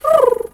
pigeon_2_emote_08.wav